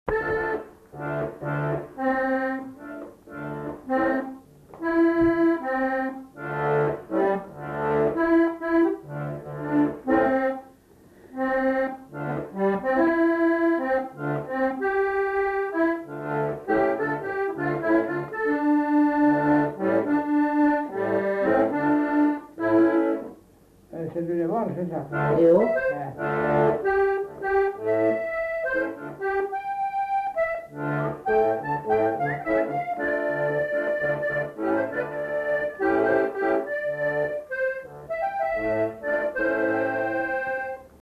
Aire culturelle : Lugues
Lieu : Pindères
Genre : morceau instrumental
Instrument de musique : accordéon diatonique
Danse : valse